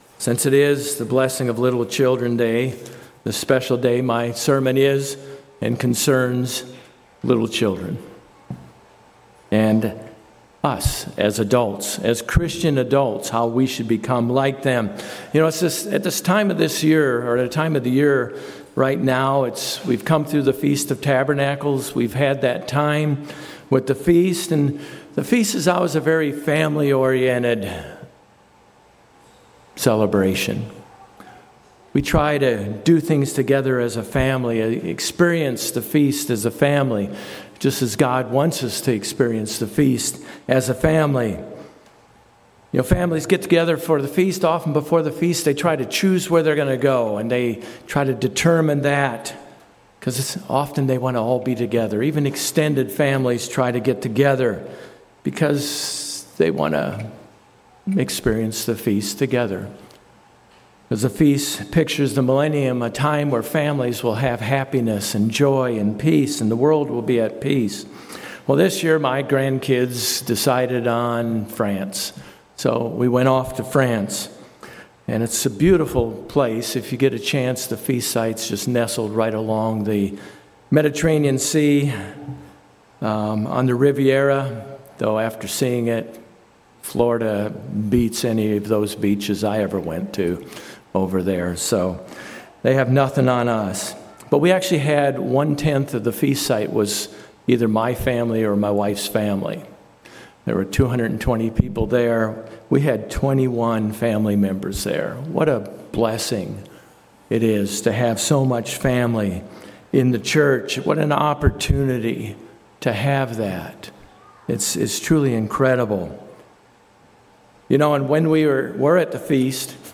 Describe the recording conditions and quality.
Given in Jacksonville, FL